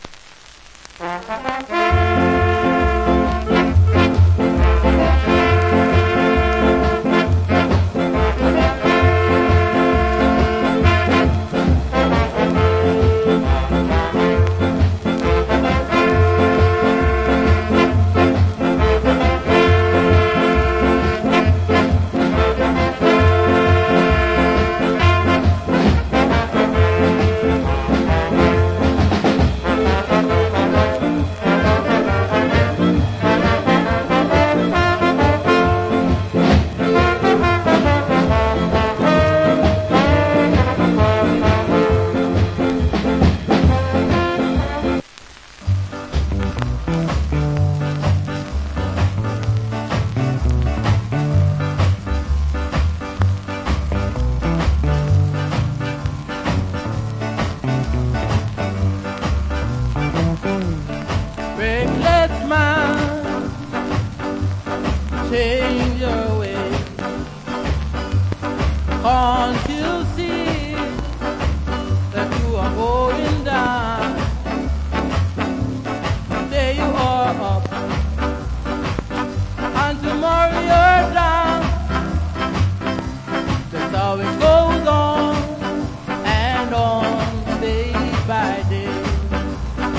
Cool Ska Inst